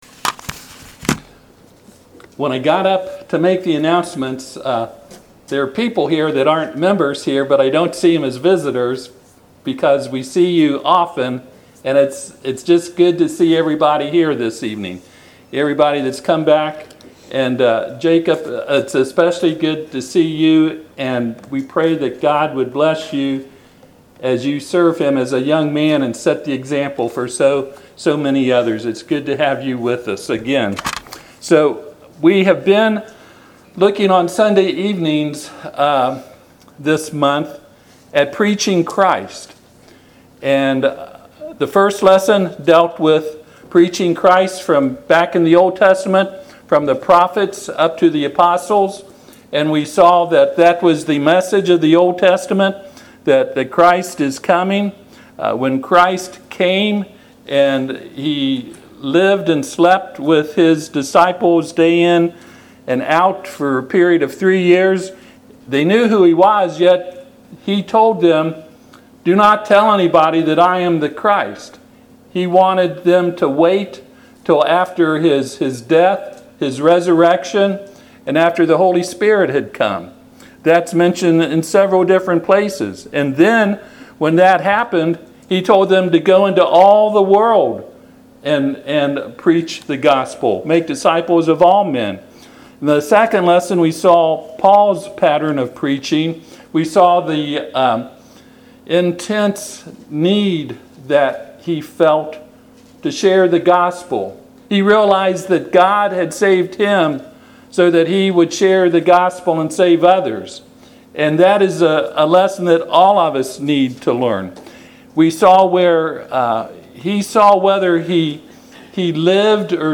Passage: 1 Timothy 1:3-7 Service Type: Sunday PM